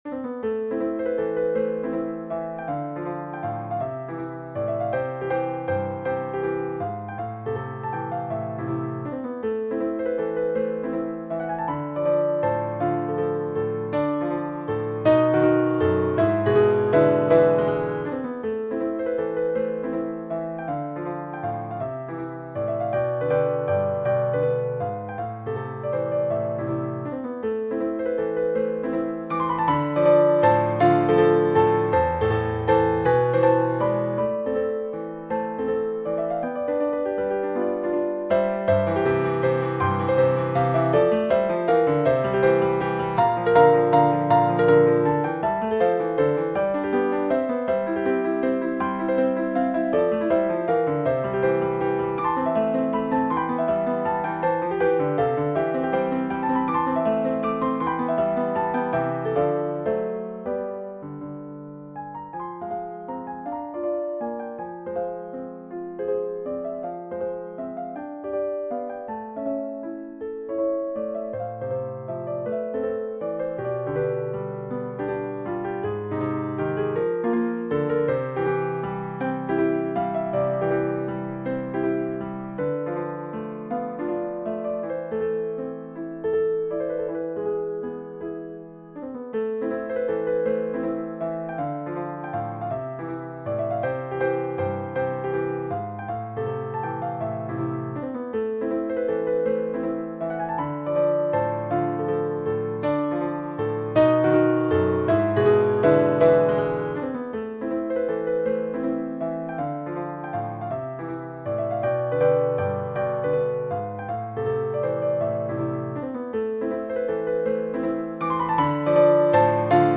Hello – This is a minor-key piano sonata in the Classical Style (but with some modern flair). It is about ten minutes long in three movements.